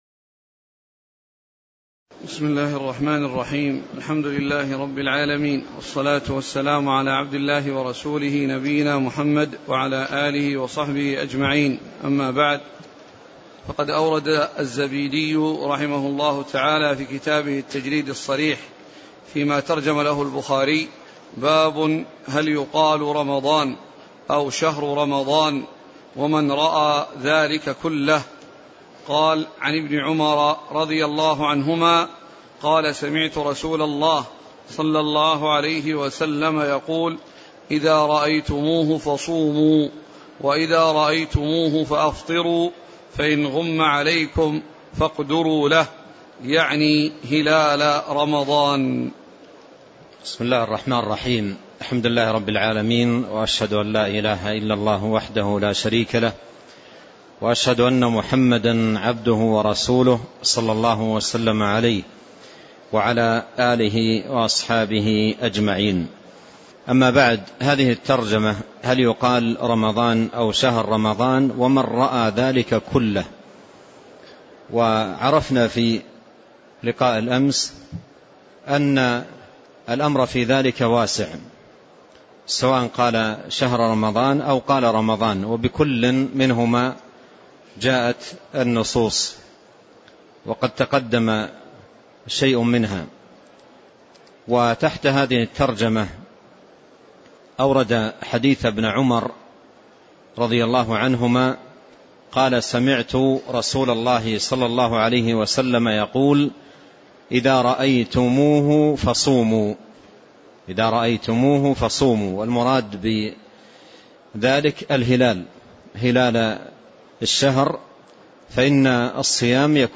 تاريخ النشر ٥ رمضان ١٤٣٤ هـ المكان: المسجد النبوي الشيخ: فضيلة الشيخ عبد الرزاق بن عبد المحسن البدر فضيلة الشيخ عبد الرزاق بن عبد المحسن البدر باب هل يقال رمضان أو شهر رمضان (02)